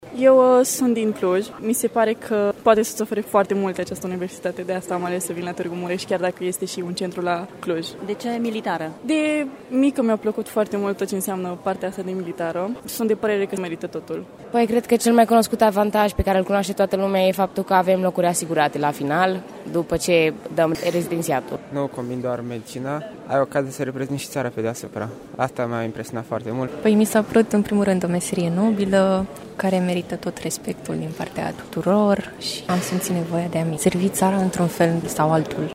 Studenții spun că procesul de pregătire e mai dificil decât la Medicina civilă, dar există mai multe avantaje: